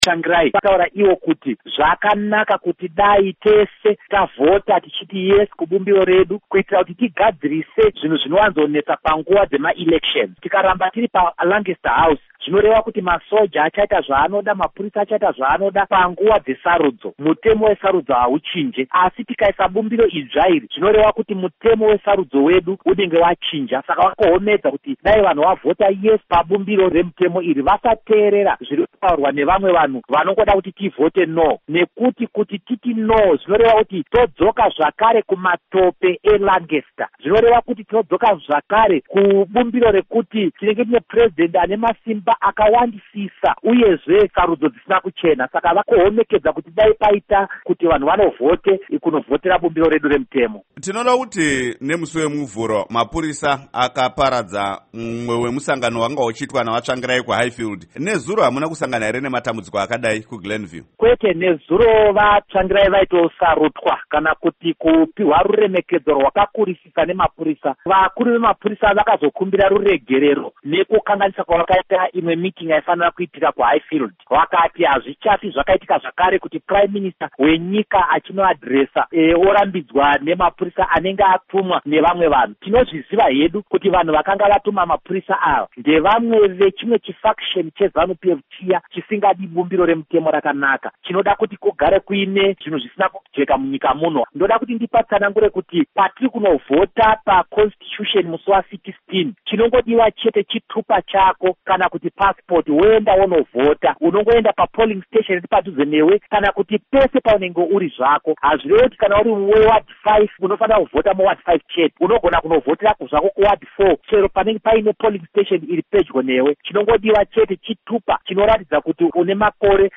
Hurukuro naVaDouglas Mwonzora